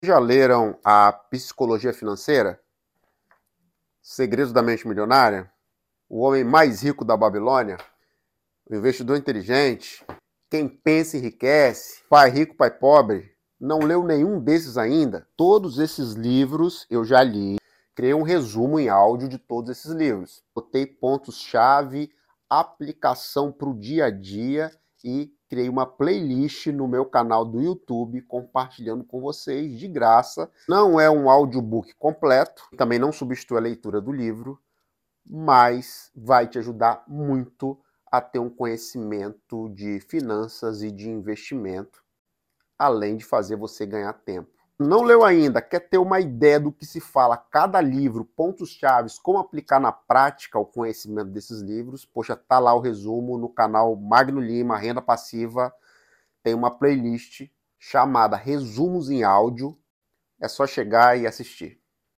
Resumo em áudio dos principais sound effects free download